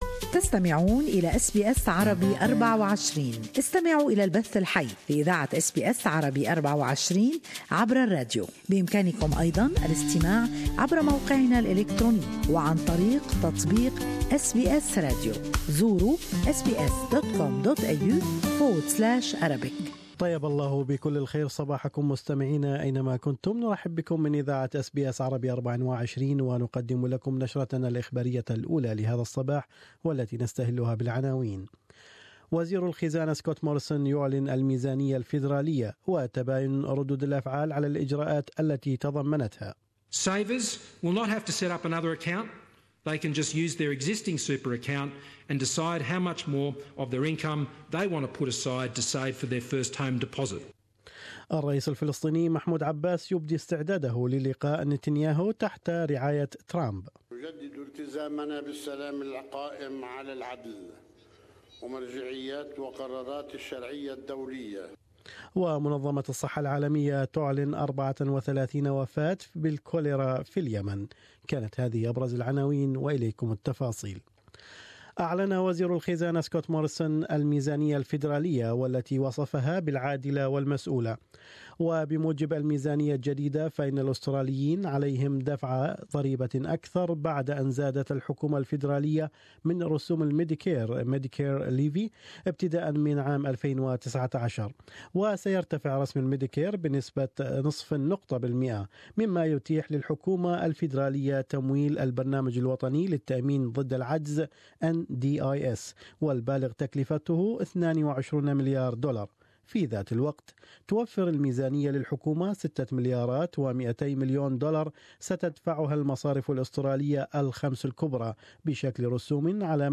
بموجب الميزانية الجديدة فان الاستراليين عليهم دفع ضريبة اكثر بعد ان زادت الحكومة الفيدرالية من رسوم المديكير وغيرها من الأخبار في نشرة الأنباء الصباحية